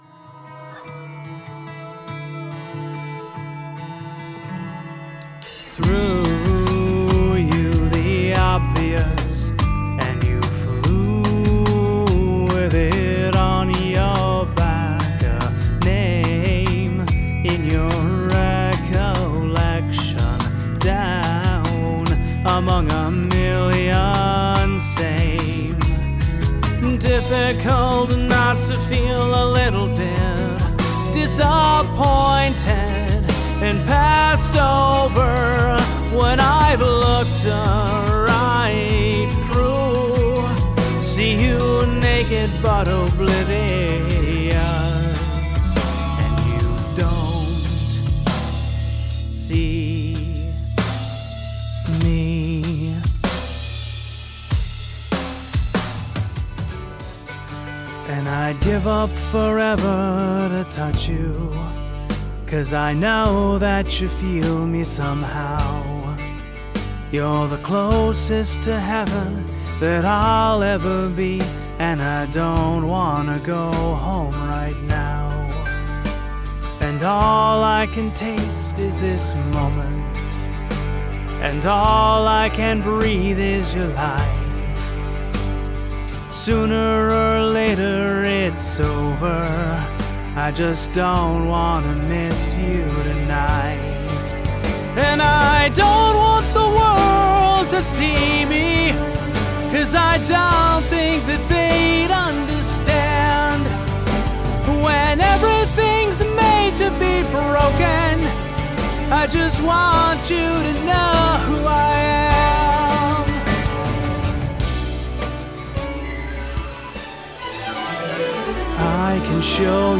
Male
Singing